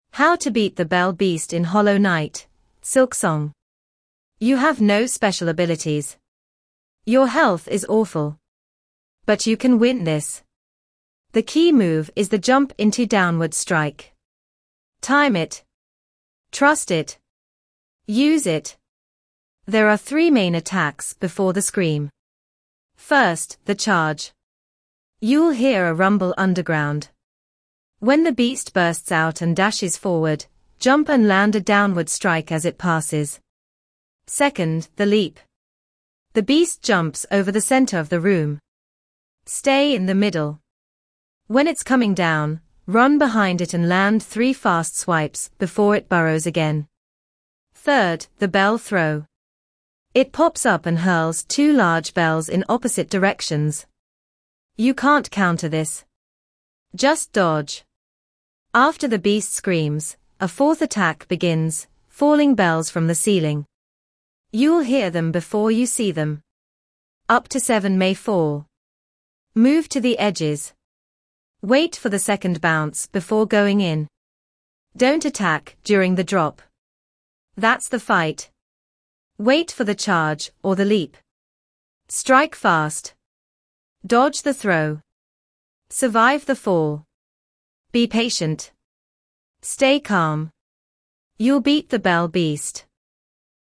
Listen to Voice Outline Boss Battle Steps